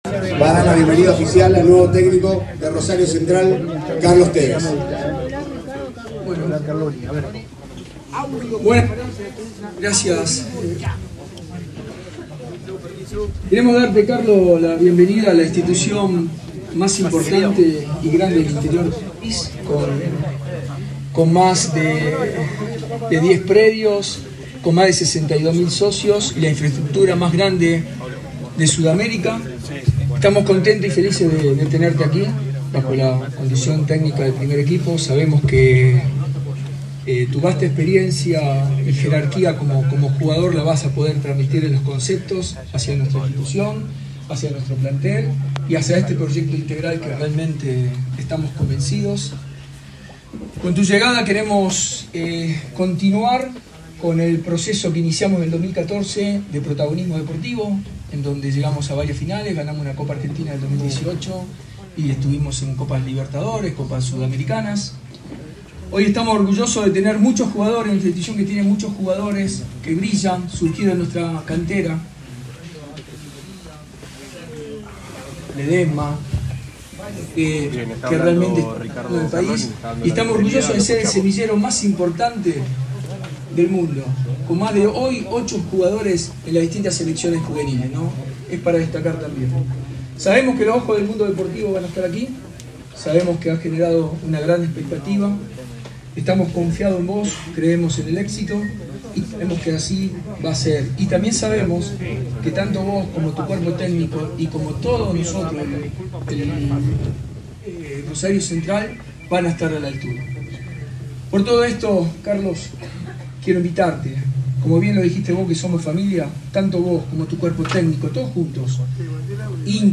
Durante la tarde de hoy, el “jugador del pueblo” dio su primera conferencia de prensa como entrenador del equipo de rosarino. La charla se realizó en el salón Centenario del estadio Gigante de Arroyito.